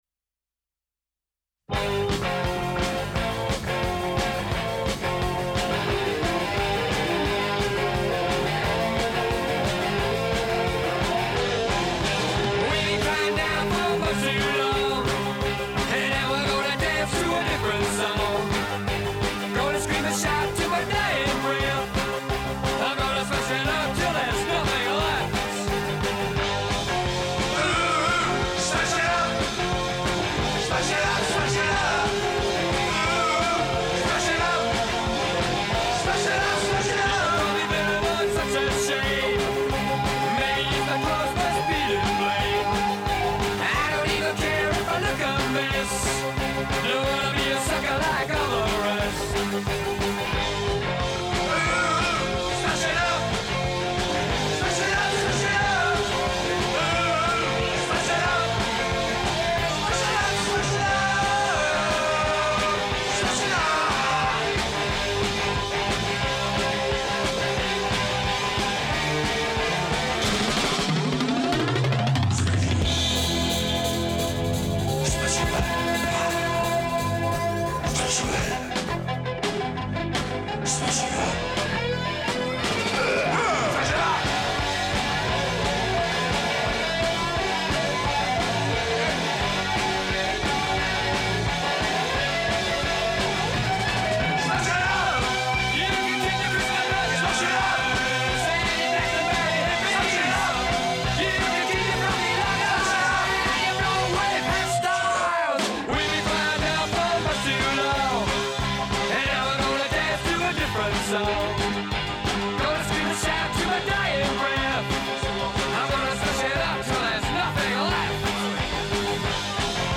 This isn’t an actual recording, but kind of a re-creation.
The music from the first round is slower and driving, with a smattering of Halloween themed songs.